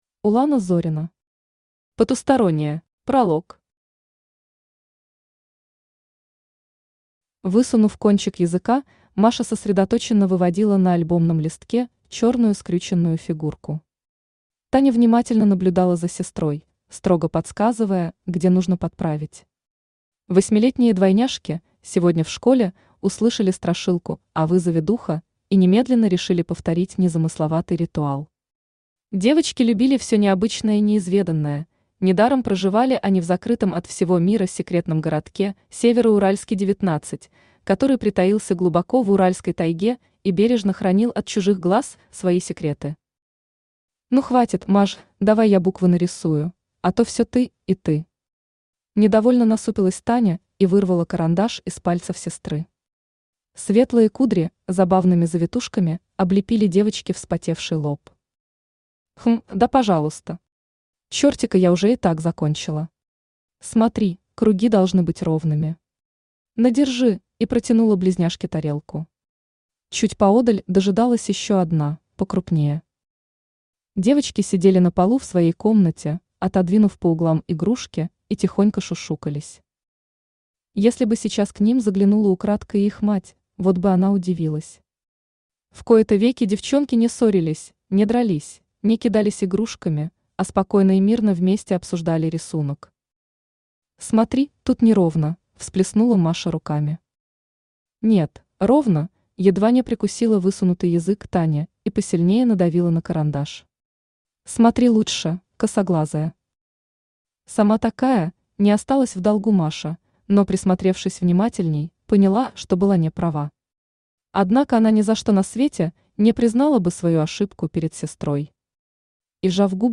Aудиокнига Потустороннее Автор Улана Зорина Читает аудиокнигу Авточтец ЛитРес.